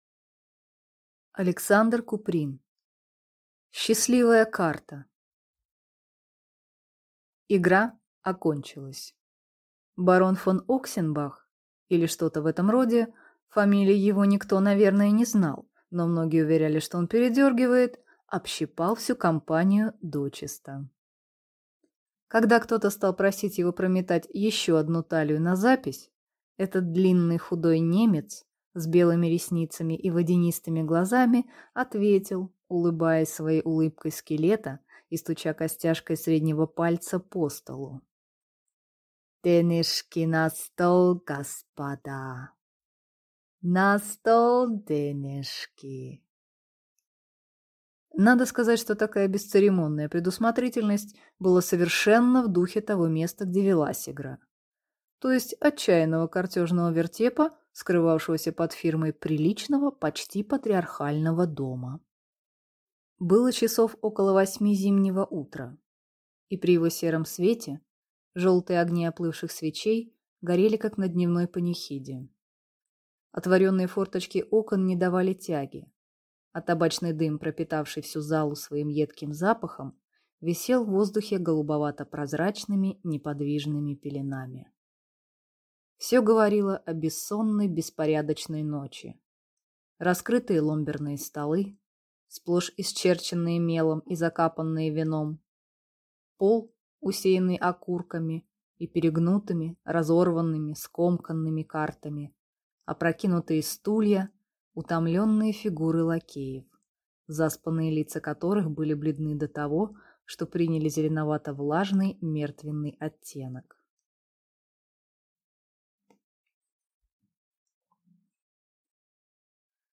Аудиокнига Счастливая карта | Библиотека аудиокниг